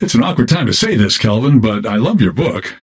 Dynamo voice line - It's an awkward time to say this, Kelvin, but I loved your book!